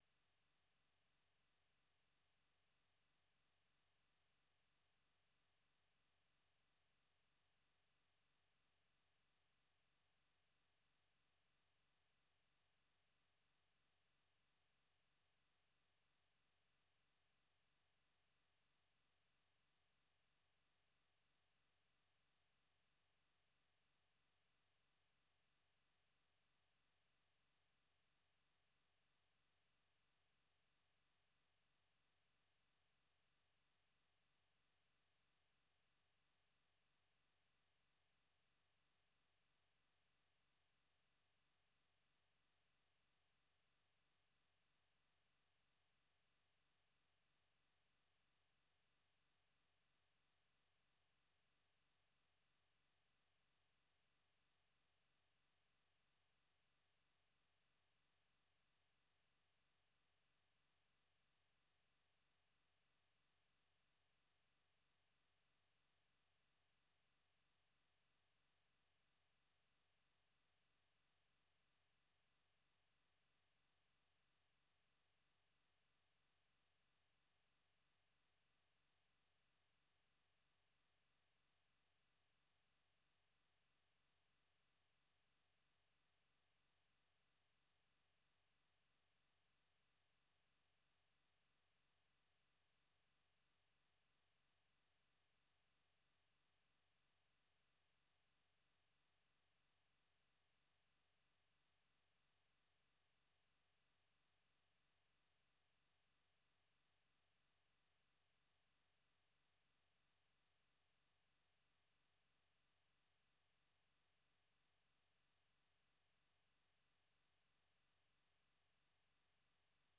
Nightline Africa is a 60-minute news magazine program highlighting the latest issues and developments on the continent. Correspondents from Washington and across Africa offer in-depth interviews, analysis and features on African arts and culture, sports, and music